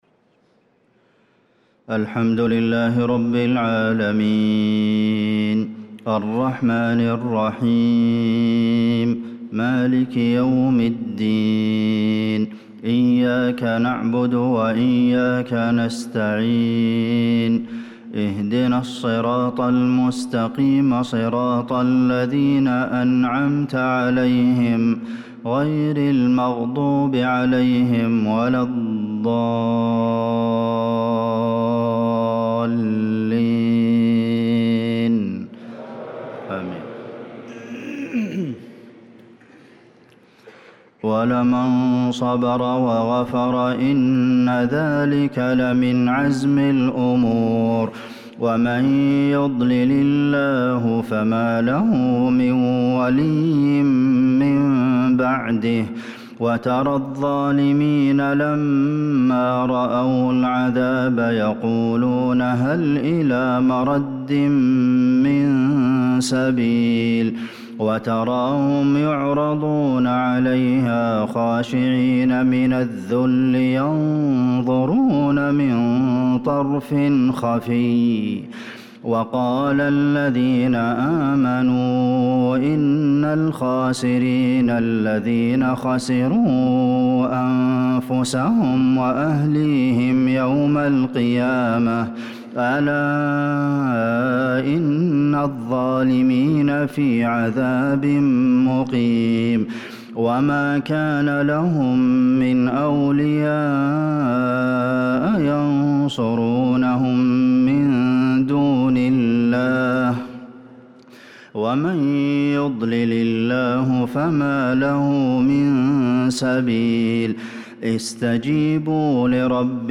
صلاة العشاء للقارئ عبدالمحسن القاسم 20 شوال 1445 هـ
تِلَاوَات الْحَرَمَيْن .